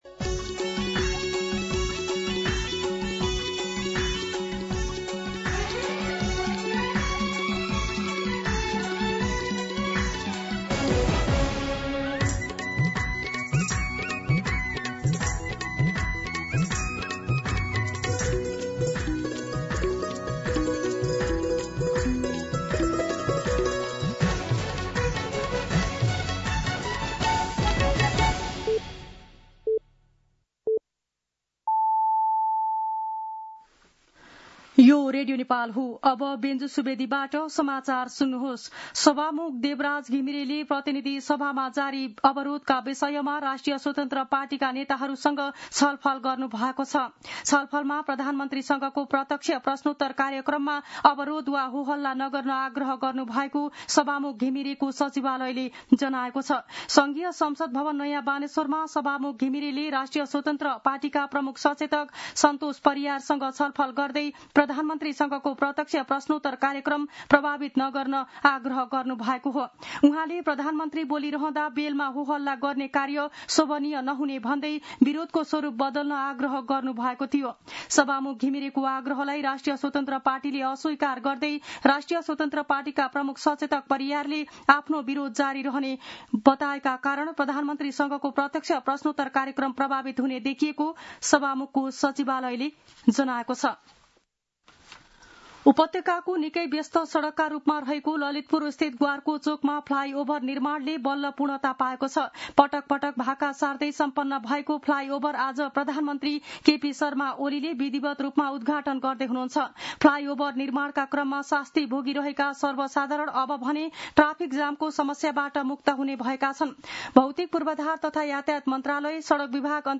मध्यान्ह १२ बजेको नेपाली समाचार : ६ असार , २०८२